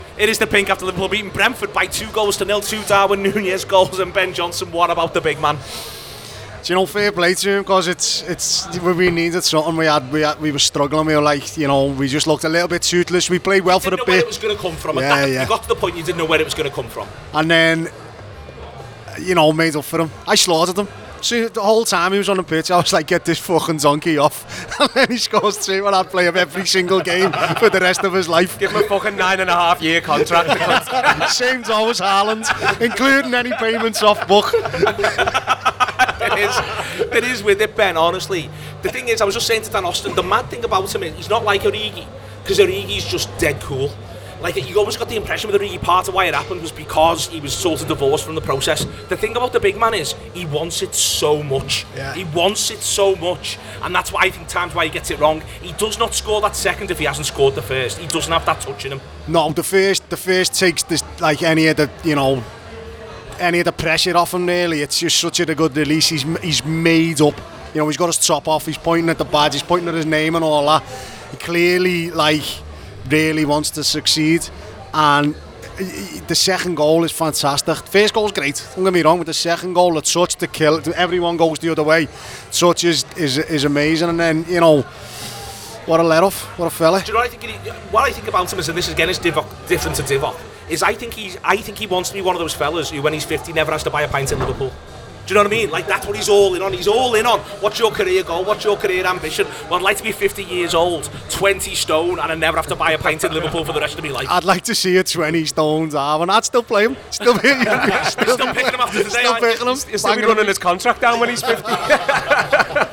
The Anfield Wrap’s post-match reaction podcast after Brentford 0 Liverpool 2 at The Gtech Community Stadium.